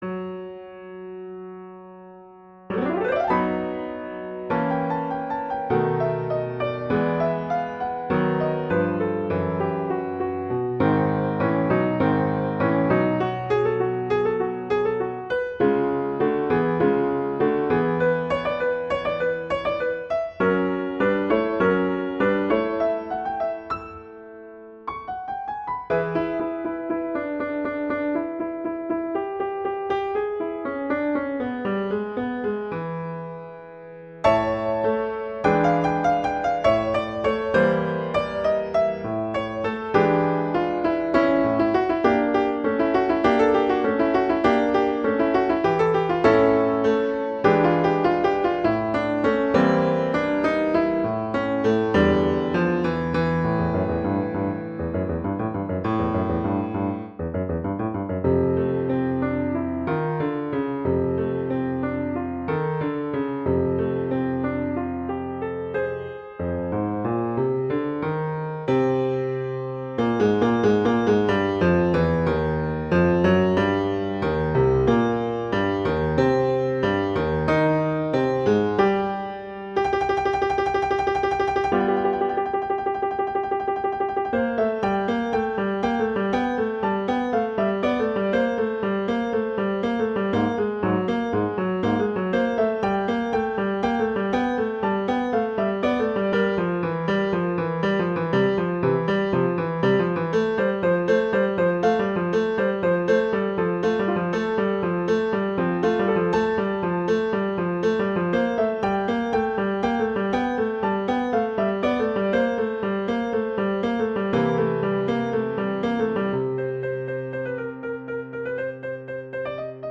classical
A minor
♩=100 BPM